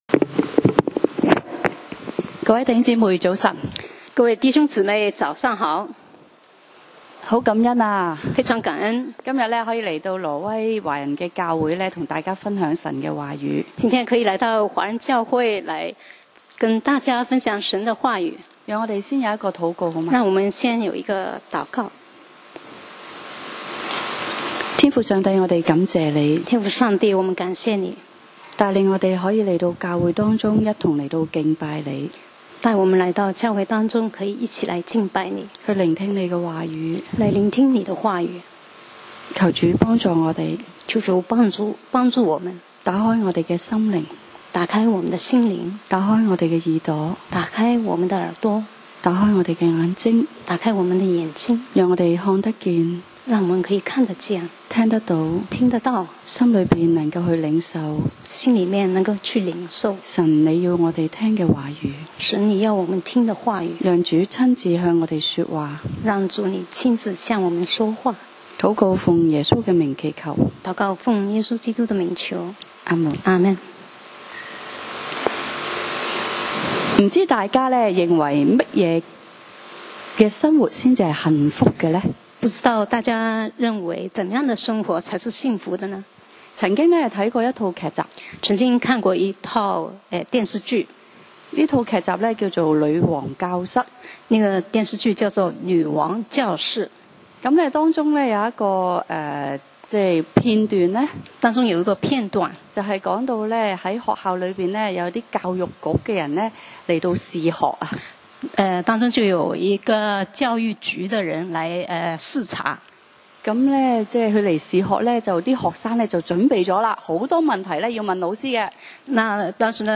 講道 Sermon 題目：幸福之家 經文 Verses：詩篇 84. 1.（可拉後裔的詩，交與伶長。